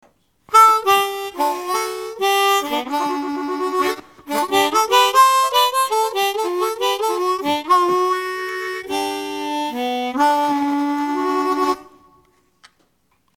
I really wanted to like this innovative design, but neither the sound nor the shape pleased me, so I was disappointed.
Harmonix C. Sound samples courtesy of my longtime musician friend and music teacher